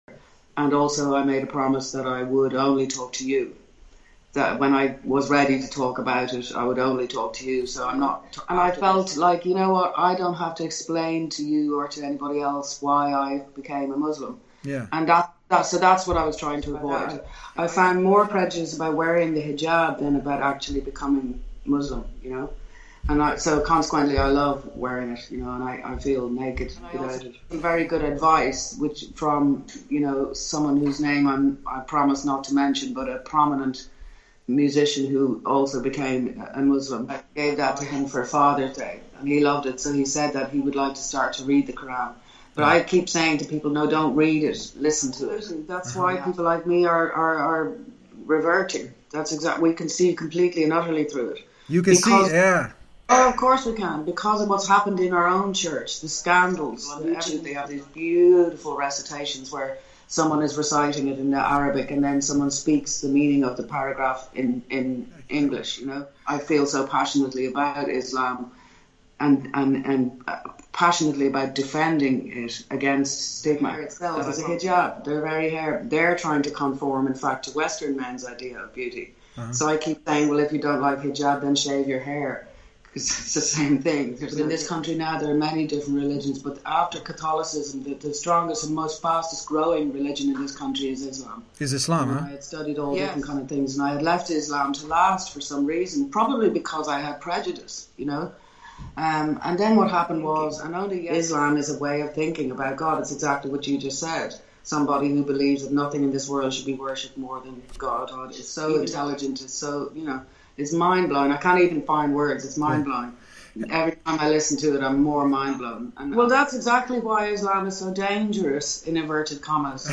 Sinéad O’Connor Exclusive Interview on TheDeenShow – Everyone Should Listen To The Quran – The Deen Show